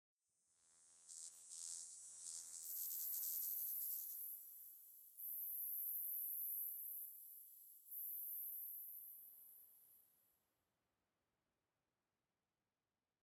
firefly_bush1.ogg